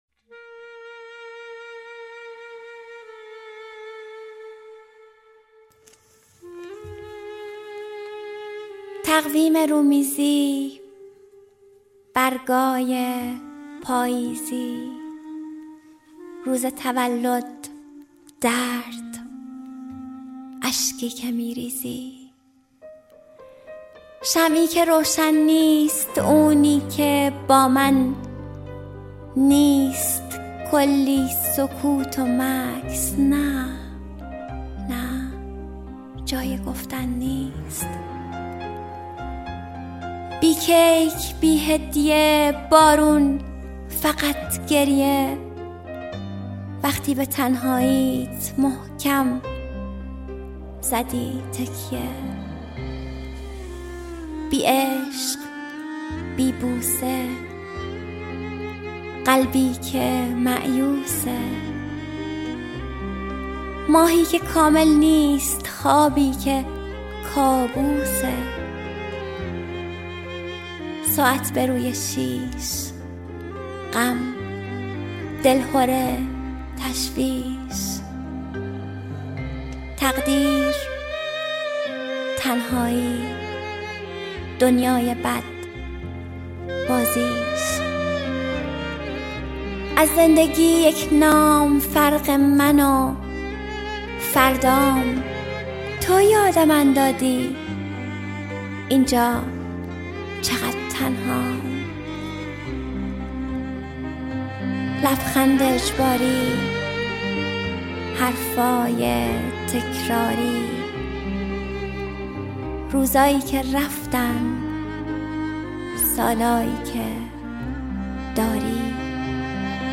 دانلود دکلمه تولد بی تو با صدای «مریم حیدرزاده»
اطلاعات دکلمه